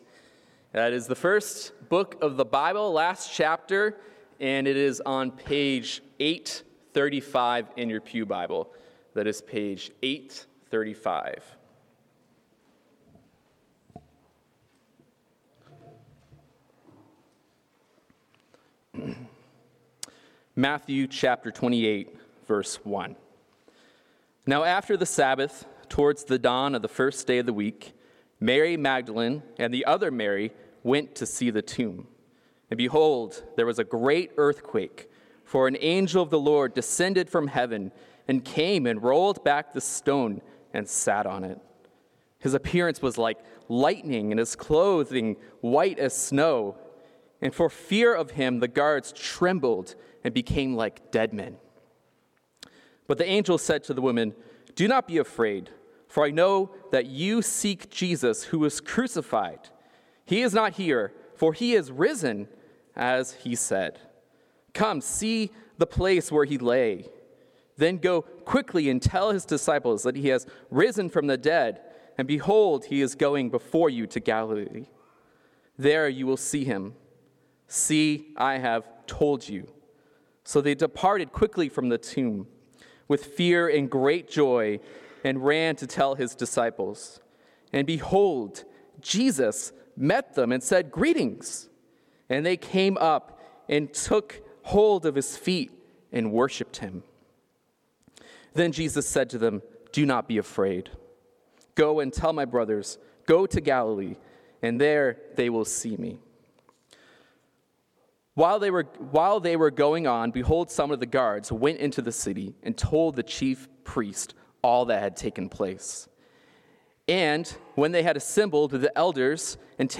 Passage: Matthew 28:1-20 Sermon